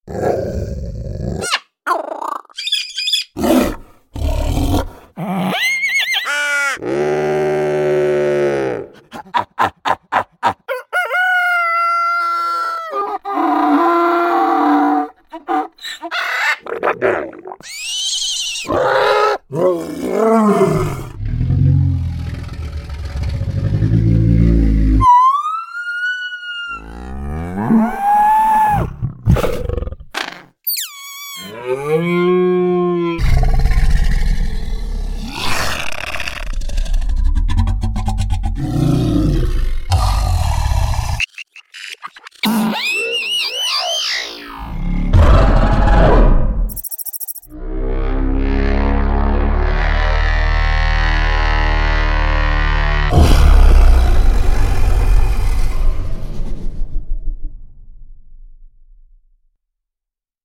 音效素材-真实的大自然中动物声音无损采集第1套：共1300组
这些素材的采集来源多样，部分来自配合录音的专业训练动物，部分则录制于动物园及野生动物保护中心，收录的内容广泛包括非洲狮、孟加拉虎、多种家畜如马和牛，以及各类珍奇鸟类和灵长类动物等。
该音效库采用专业设备进行高标准录制，确保了声音的清晰度和真实感。